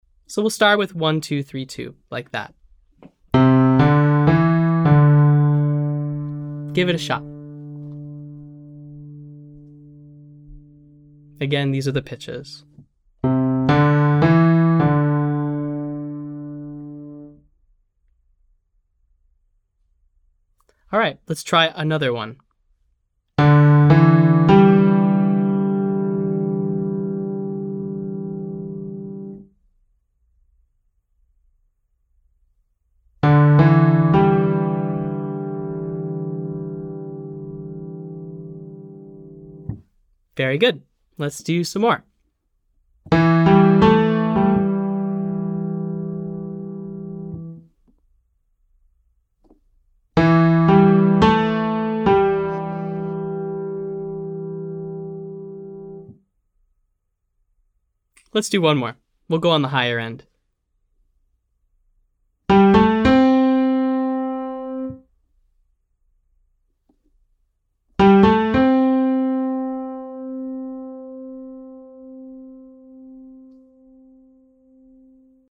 From: R&B Daily Practice for Low Voices
I’ll give you 3-4 pitches of the pentatonic scale, and your job is to create your own rhythm.
Exercise: Give pitches & have students create rhythm; can repeat notes, change directions, etc.